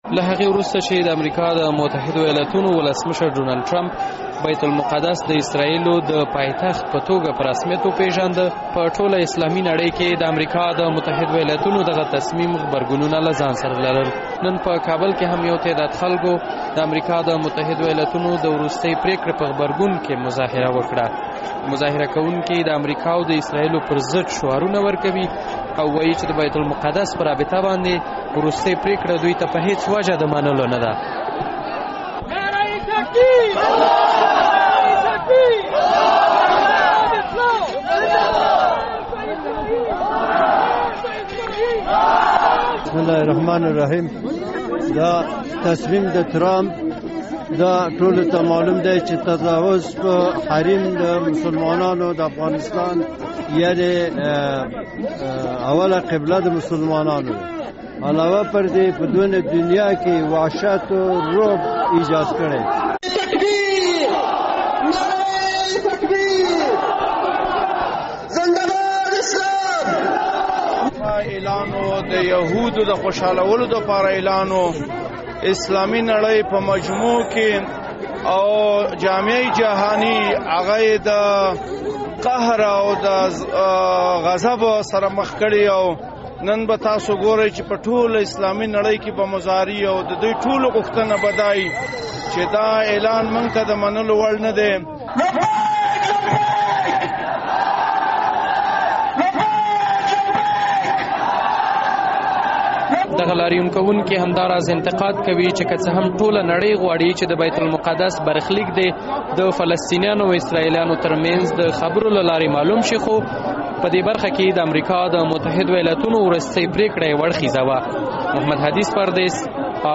له سیمې څخه دغه راپور چمتو کړی دی دی: